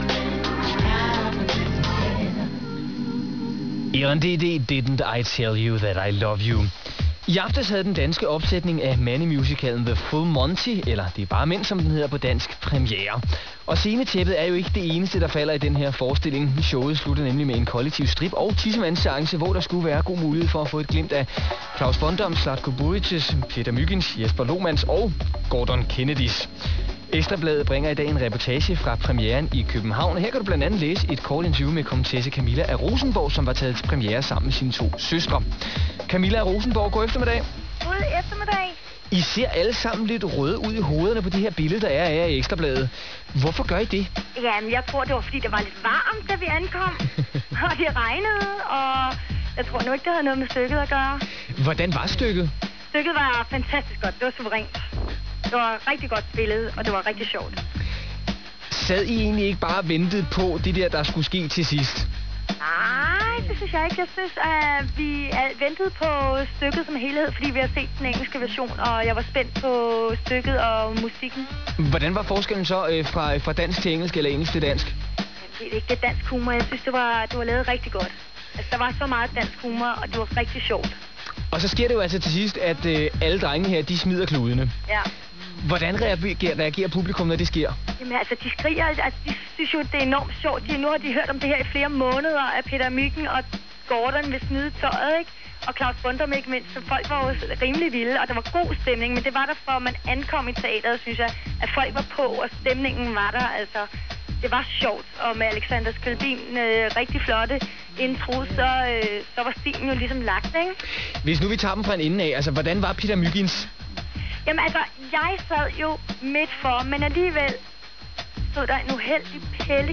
RADIO INTERVIEW I P3 MED